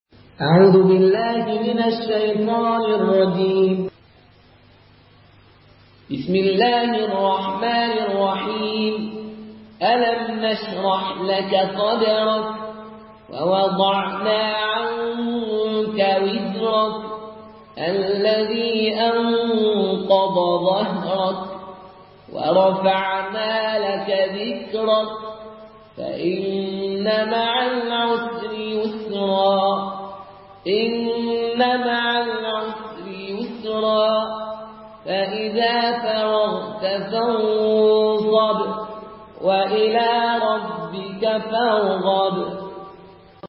Une récitation touchante et belle des versets coraniques par la narration Qaloon An Nafi.
Murattal